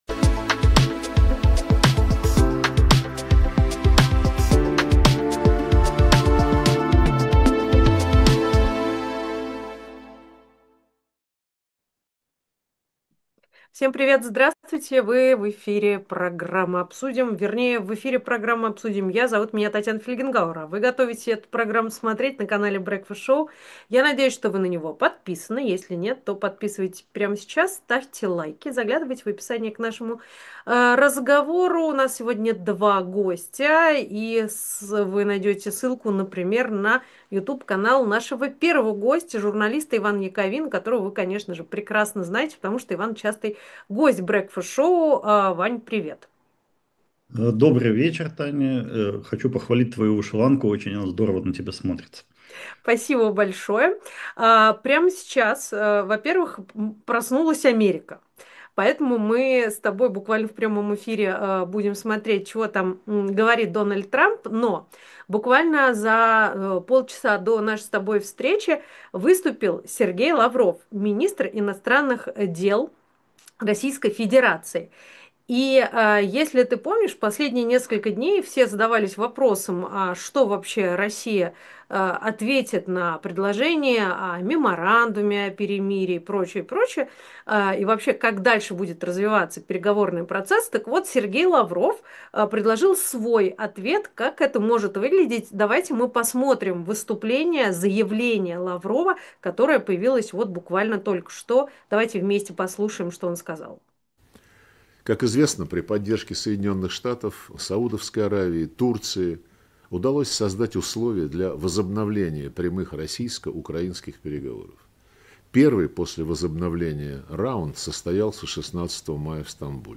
Программу ведет Татьяна Фельгенгауэр.